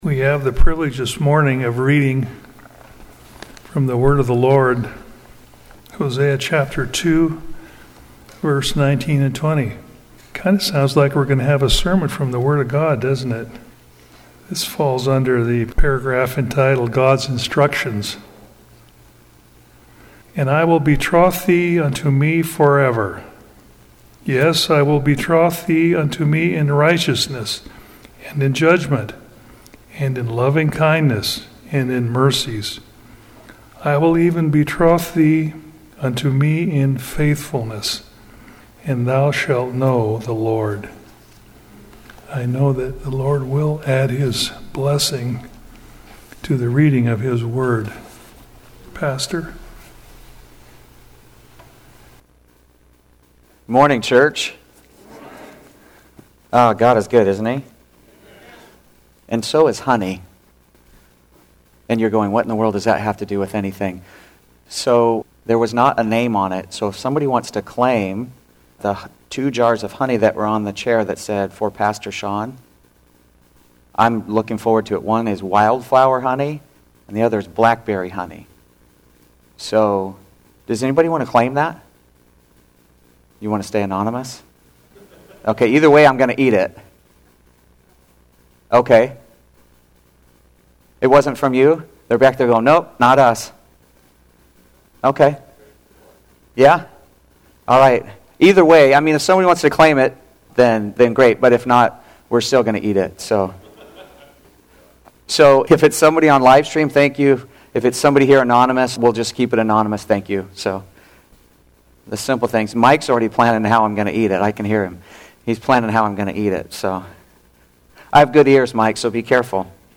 Seventh-day Adventist Church, Sutherlin Oregon
Sermons and Talks